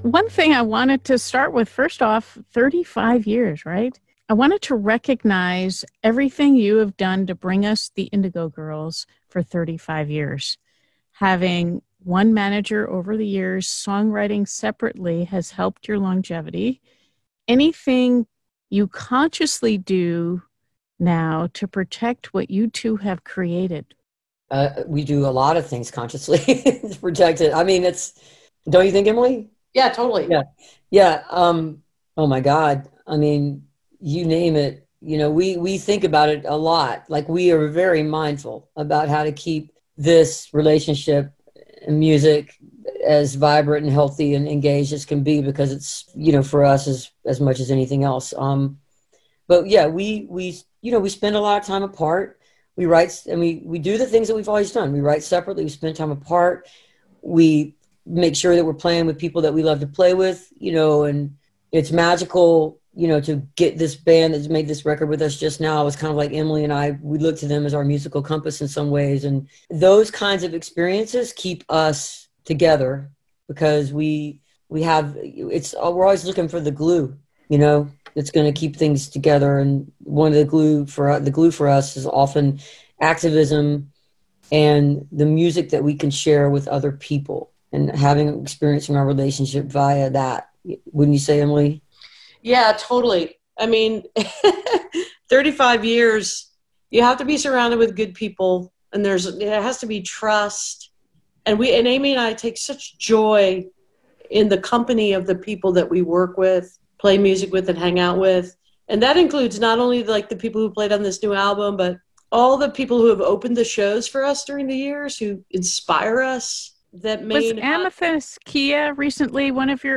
(captured from the web broadcast)
03. interview (3:50)